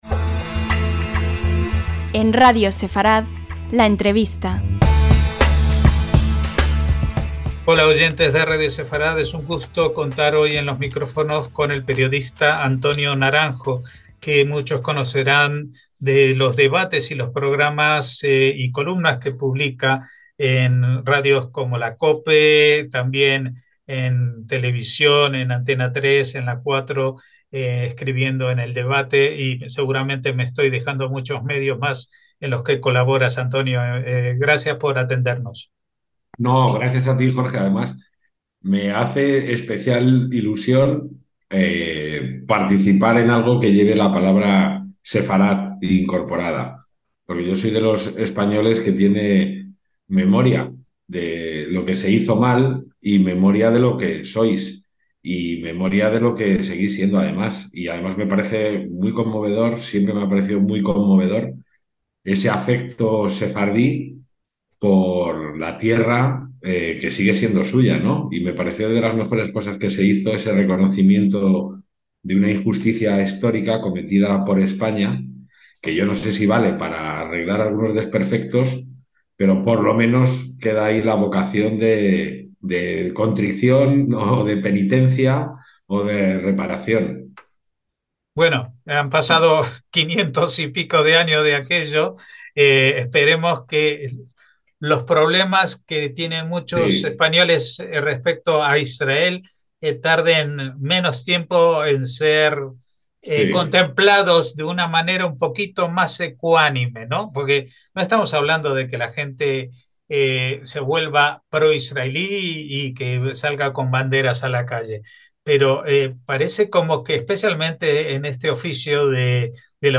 LA ENTREVISTA - Antonio Naranjo es un periodista español conocido por su participación en programas televisivos de Antena 3 y La Sexta, de emisoras de radio como la COPE y por sus artículos en diferentes medios, entre ellos, El Debate.